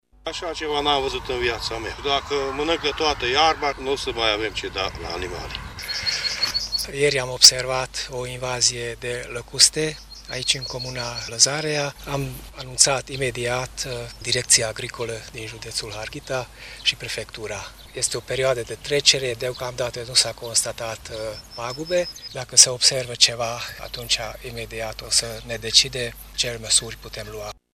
a stat de vorbă cu agricultorii şi cu Vice-primarul comunei Lăzarea